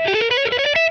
GUITAR LOOPS - PAGE 1 2 3 4